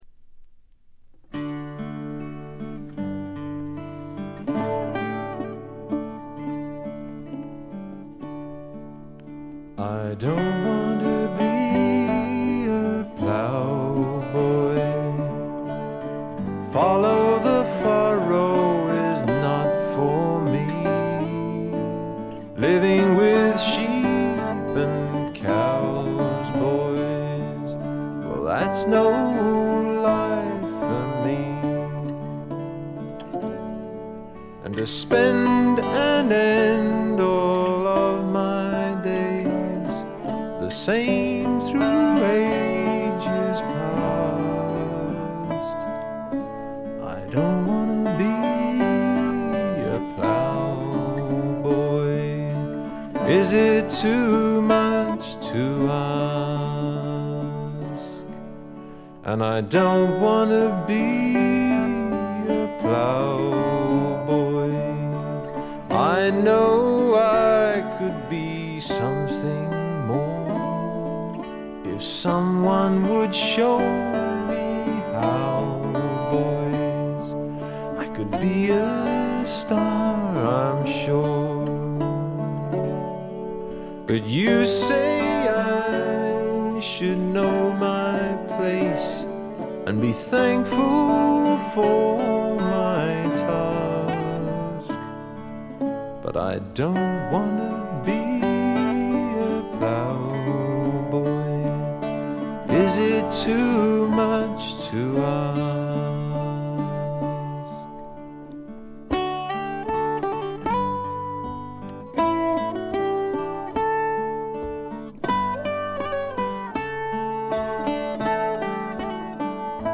ごくわずかなノイズ感のみ。
試聴曲は現品からの取り込み音源です。
Vocals Guitars
Keyboards
Balalaika
Bass Vocals
Recorded at Turboways Studio, Lonbdon 1985/6.